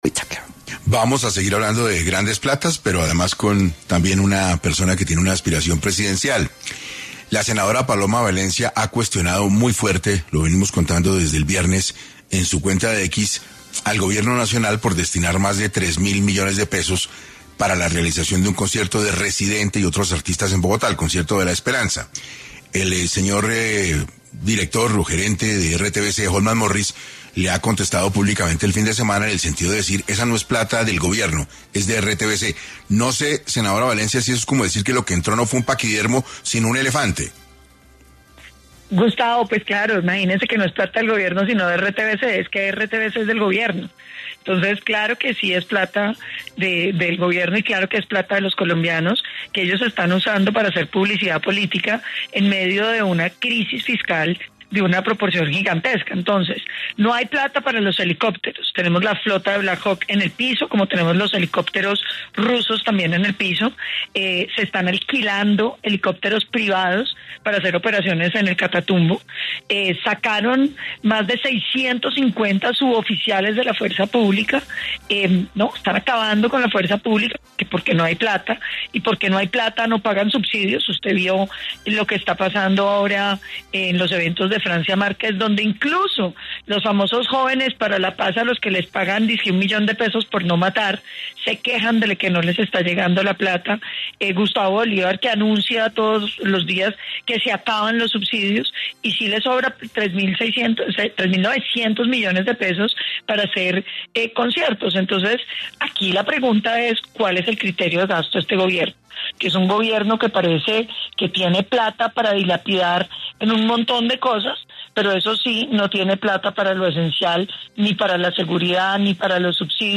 En 6AM de Caracol Radio estuvo Paloma Valencia, senadora de la república, que criticó las políticas de repatriación del presidente Petro, además expresó su preocupación por la inversión del presupuesto Nacional.
Precisamente, la senadora Valencia estuvo en 6AM de Caracol Radio, en donde no solo cuestionó las recientes tensiones diplomáticas que ha generado el Gobierno, sino que también expresó su inconformidad con la inversión del presupuesto Nacional en temas, que a su consideración, son irrelevantes.